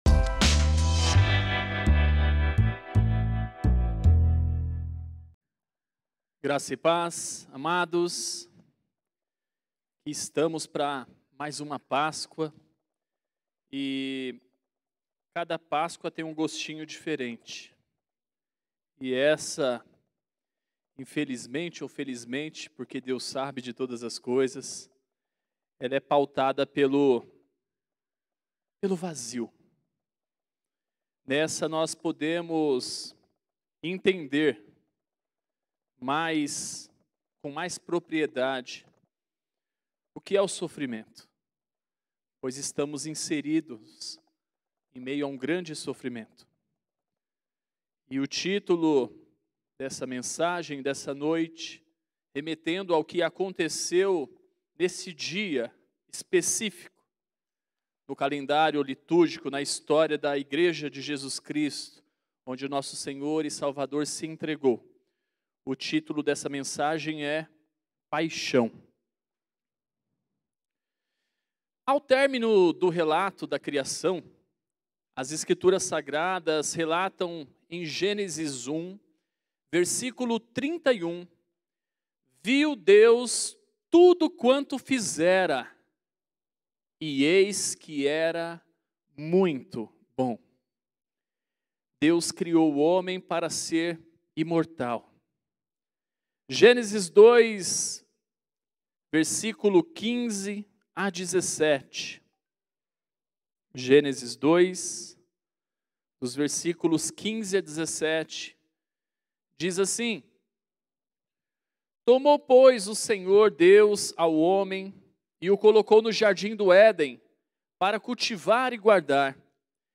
Mensagem ministrada
na Sexta Feira da Paixão de 2021.